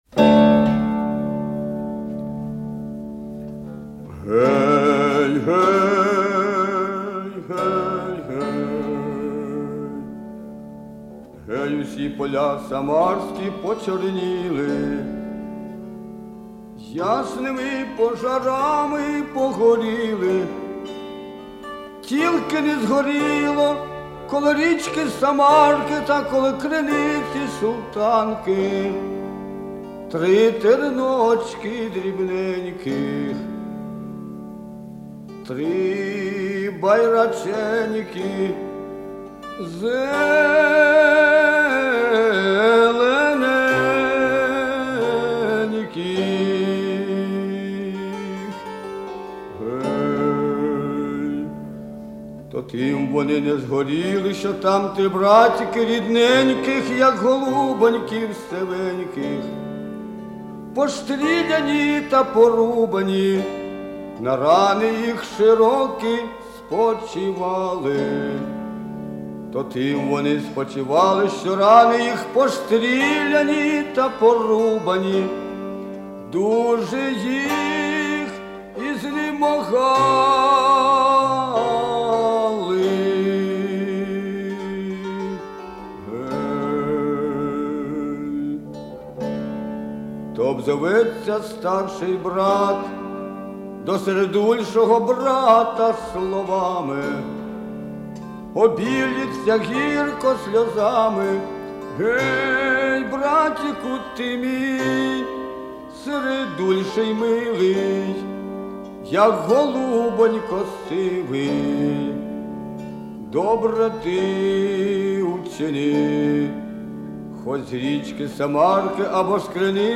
дума XVII ст.